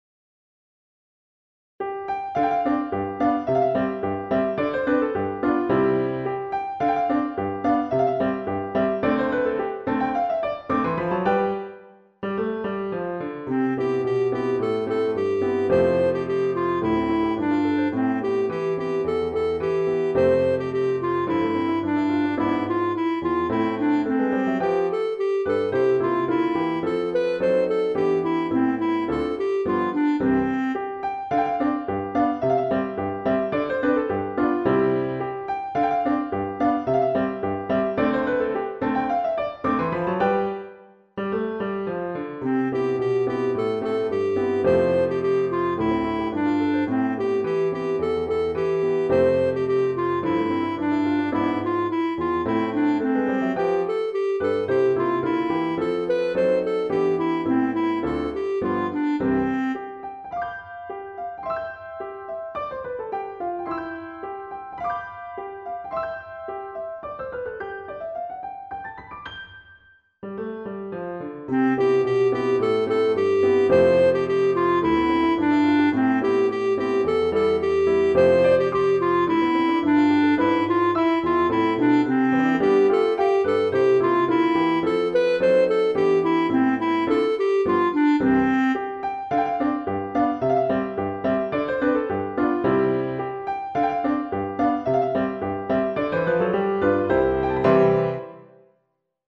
Chorale d'Enfants et Piano